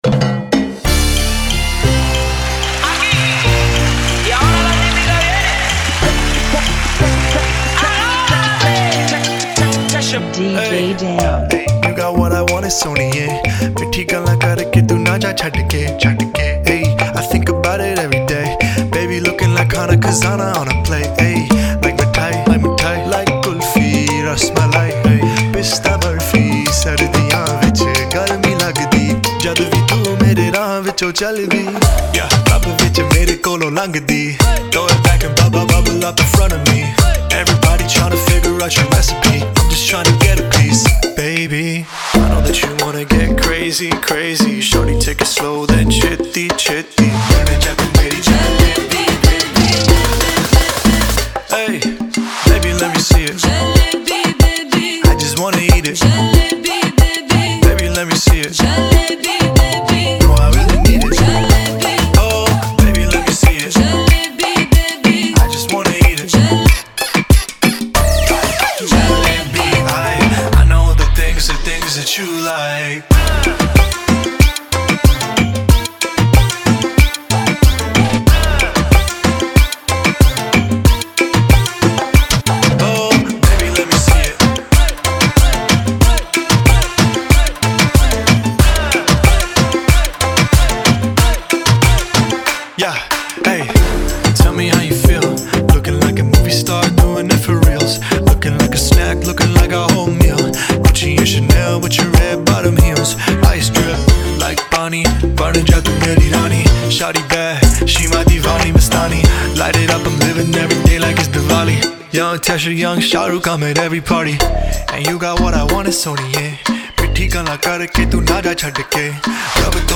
93 BPM
Genre: Salsa Remix